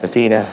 synthetic-wakewords
ovos-tts-plugin-deepponies_Kanye West_en.wav